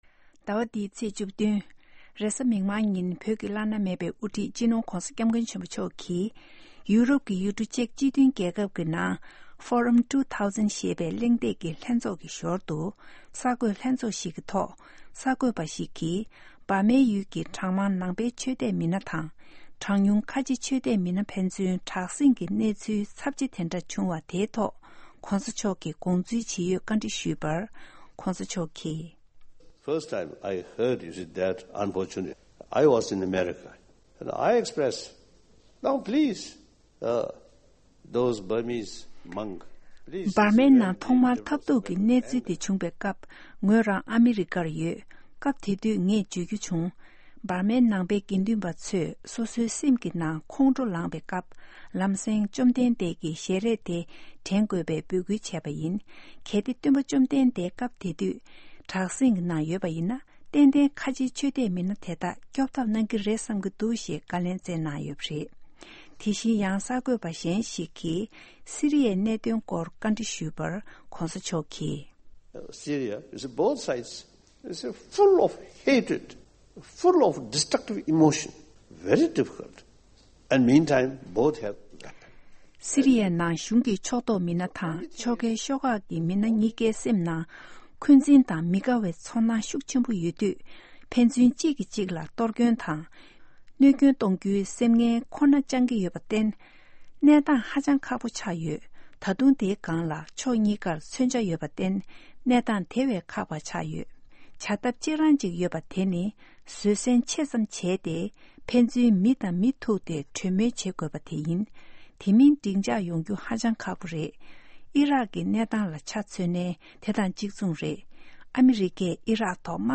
བོད་ཀྱི་བླ་ན་མེད་པའི་དབུ་ཁྲིད་སྤྱི་ནོར་༧གོང་ས་༧སྐྱབས་མགོན་ཆེན་པོ་མཆོག་གིས་ཅེག་སྤྱི་མཐུན་རྒྱལ་ཁབ་ཀྱི་Forum 2000ཞེས་པའི་གླེང་སྟེགས་ཀྱི་ལྷན་ཚོགས་སྐབས་སུ་གསར་འགོད་ལྷན་ཚོགས་ཐོག་བཀའ་ལན་བསྩལ་གནང་མཛད་པ་